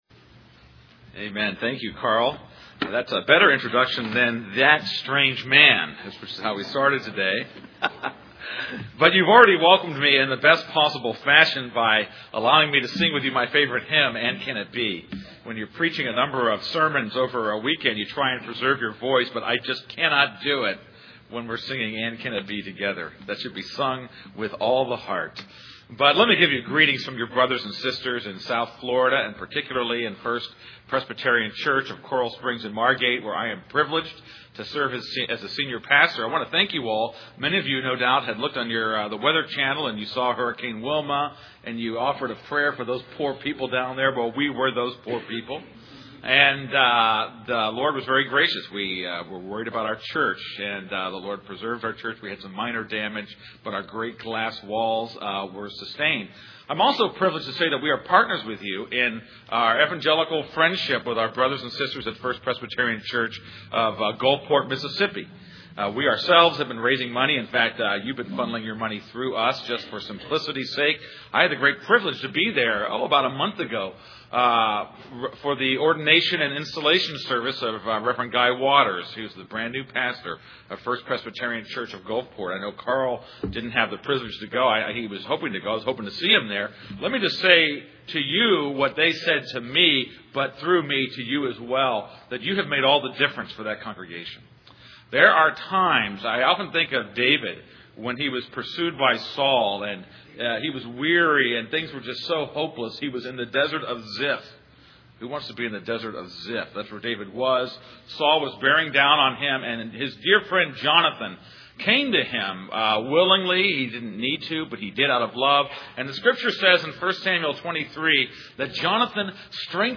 This is a sermon on John 4:1-30.